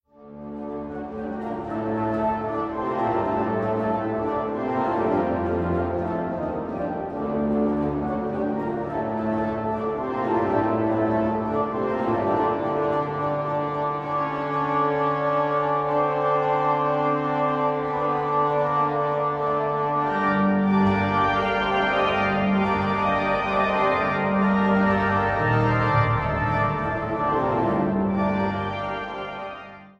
Ladegast-Orgel im Dom zu Schwerin